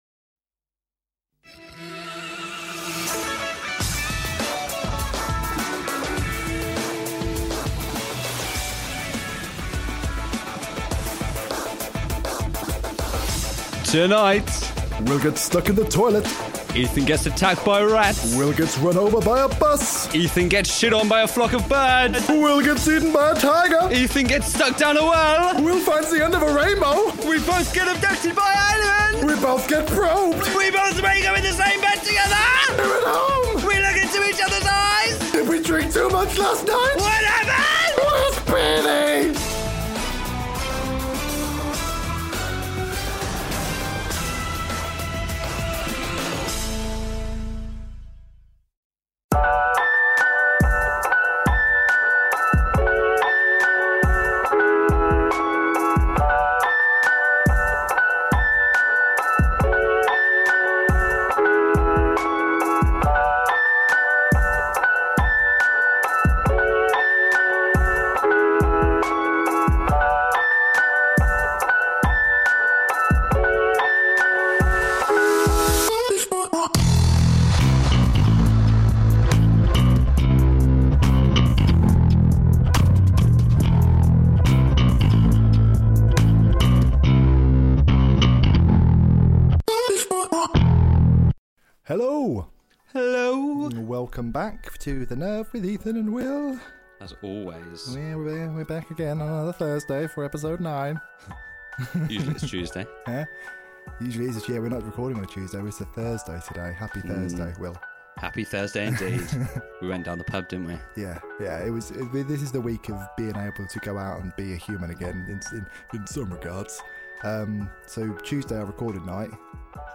This podcast is full of swearing so if that offends you, this podcast isn't right for you!
(we own no music in this podcast apart from our theme song)